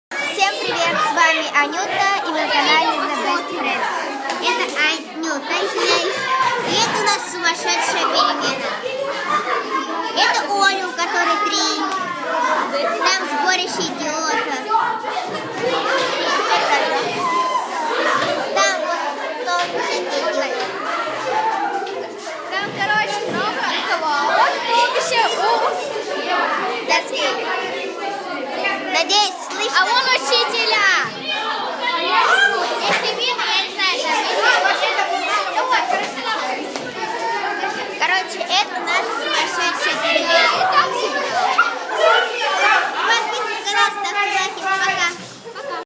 School recess (25
• Category: School Break 1301